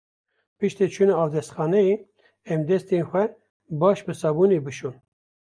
Pronounced as (IPA) /bɑːʃ/